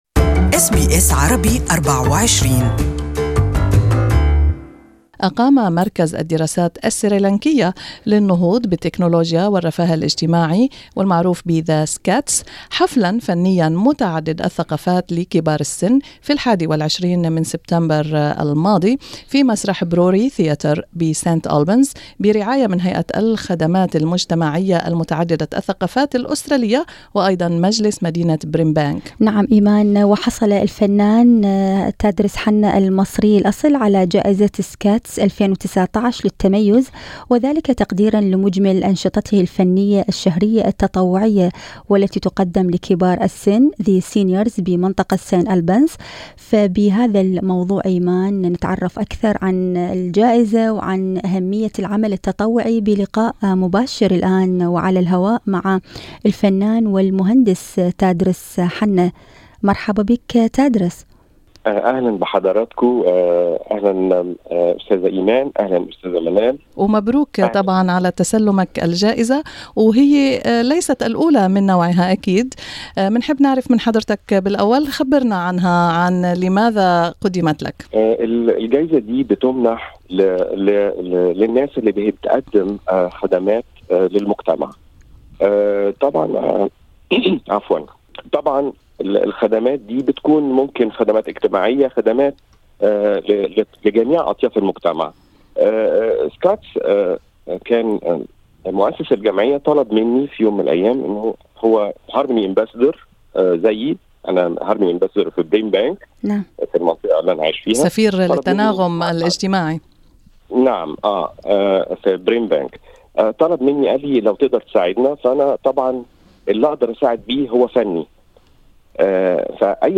This is an interview in Arabic with artist